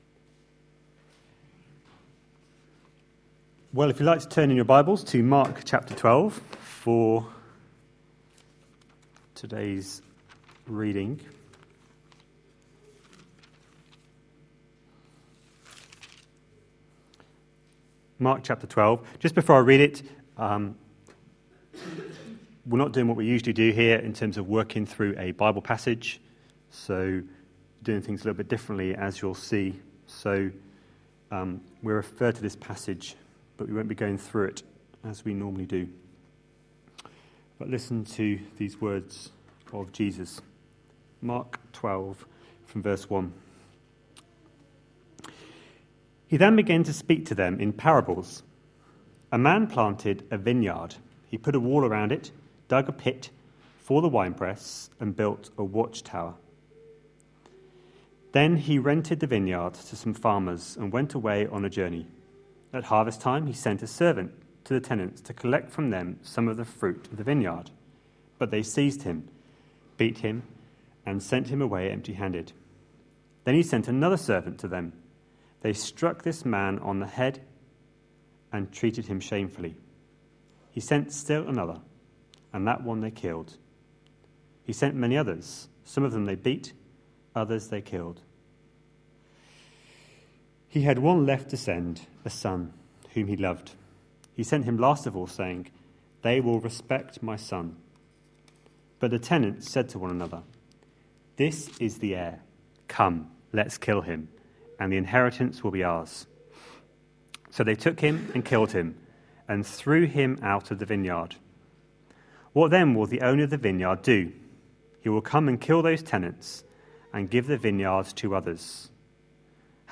A sermon preached on 22nd December, 2013.